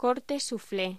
Locución: Corte souflé
voz